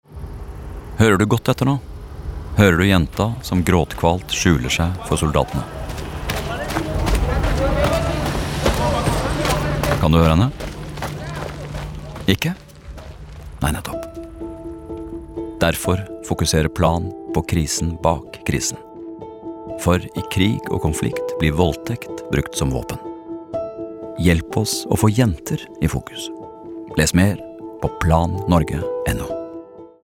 Månedens vinner evner å dra oss lenger inn i radiohøyttaleren for hver gang, og vi regner med at flere lyttere vil spille dem om igjen om de kunne.